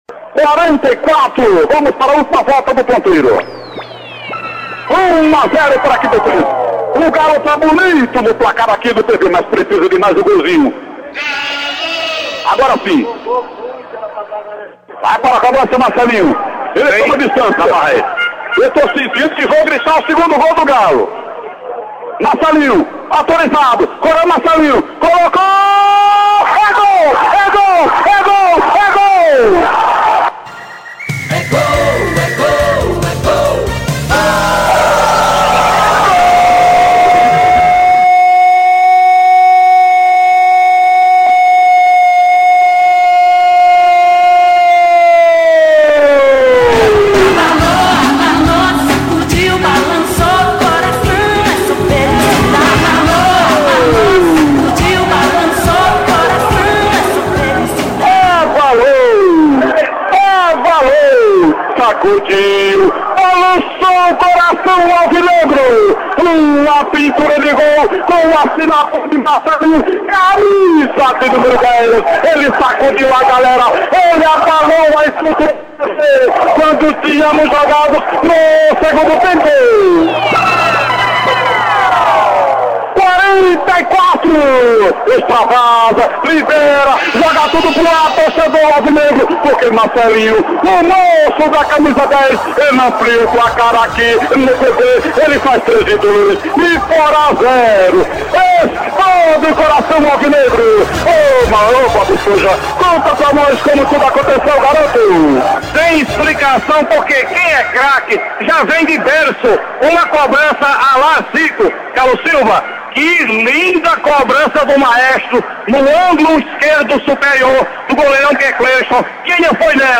A emoção do gol na voz